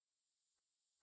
Prayer and Worship Service
No sermon this week, as we gathered for an extended time of prayer and worship.